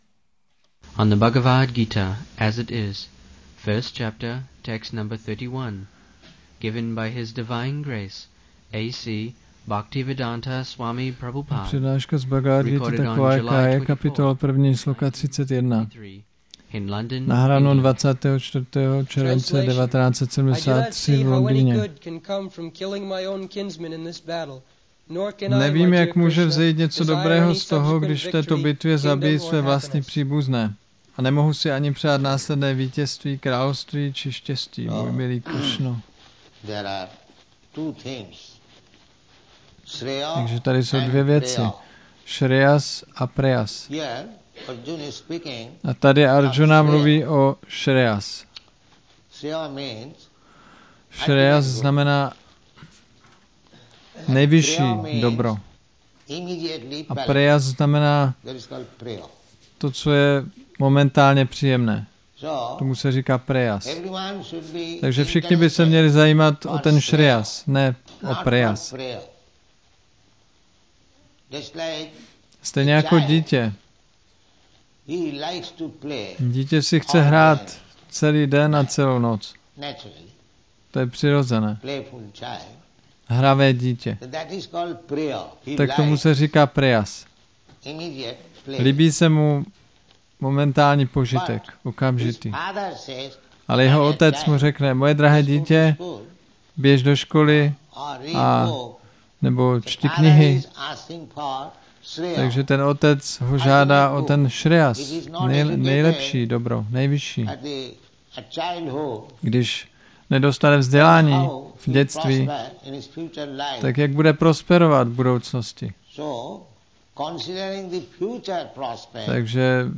1973-07-24-ACPP Šríla Prabhupáda – Přednáška BG-1.31 London